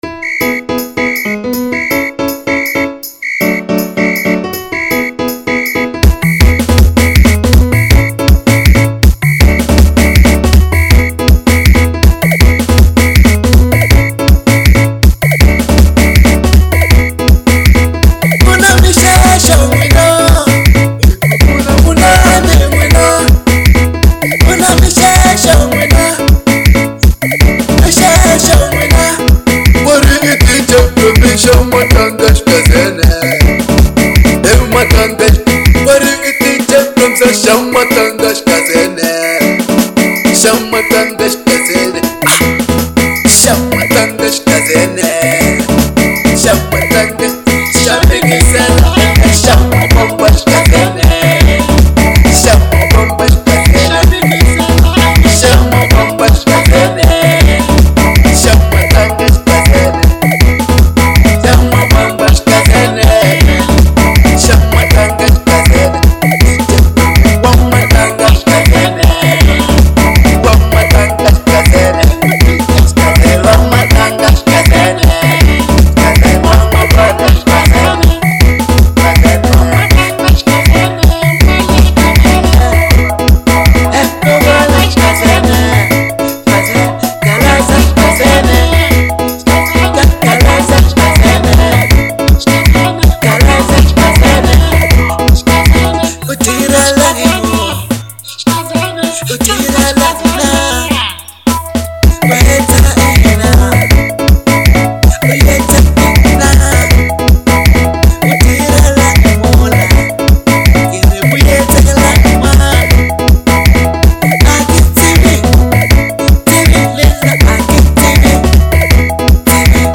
04:07 Genre : Xitsonga Size